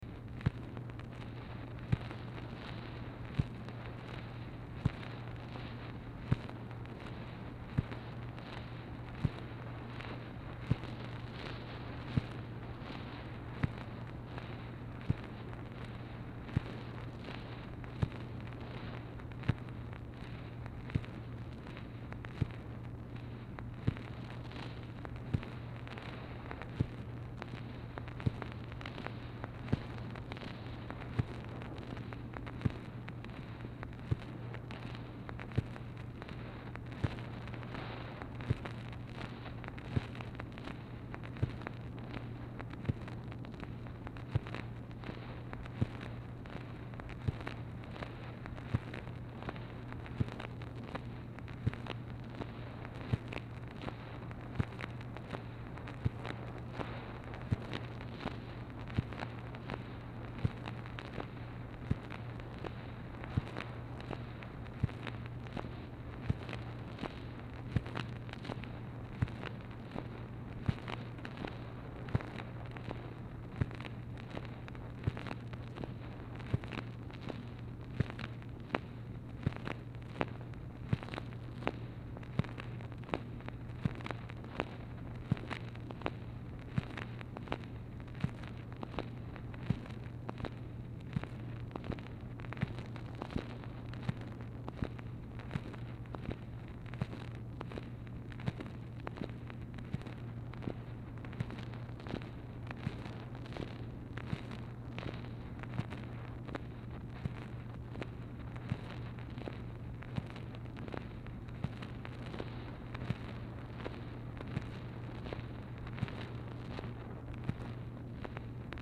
Telephone conversation # 2938, sound recording, MACHINE NOISE, 4/9/1964, time unknown | Discover LBJ
Format Dictation belt
Specific Item Type Telephone conversation